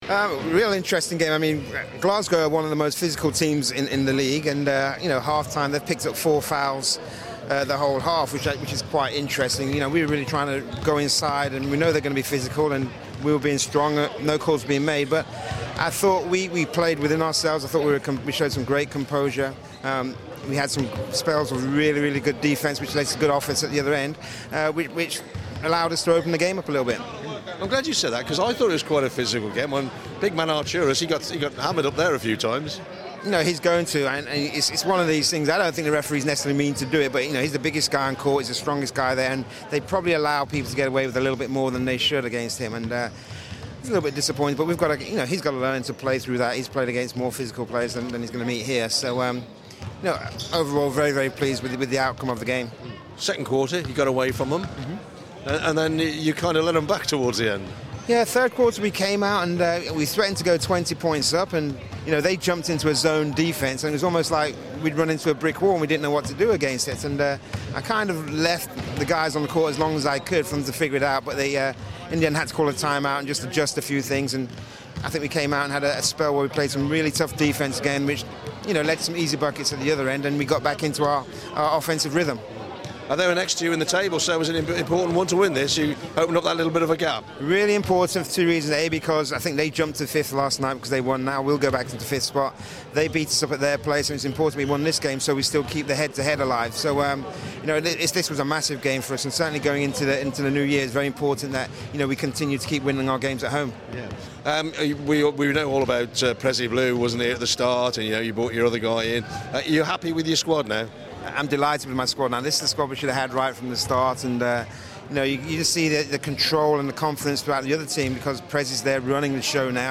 talks to BBC Hereford and Worcester after the 83-70 home win against Glasgow Rocks